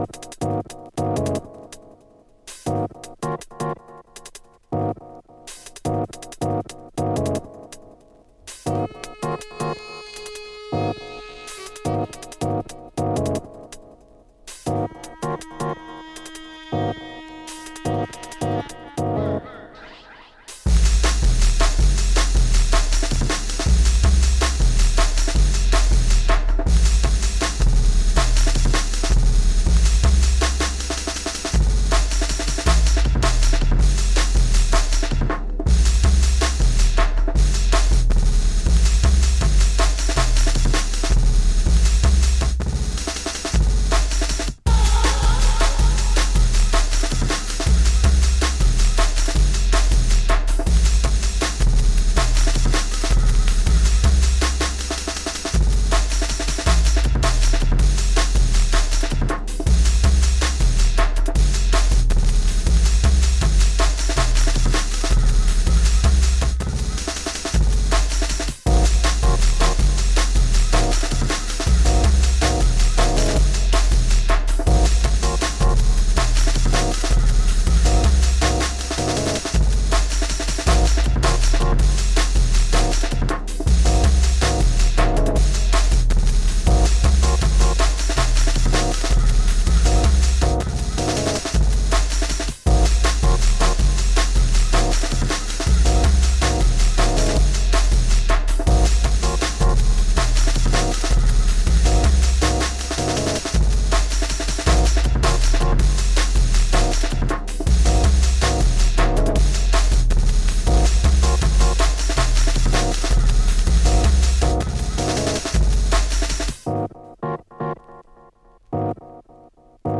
Jungle-techno essencial, a olhar novas possibilidades.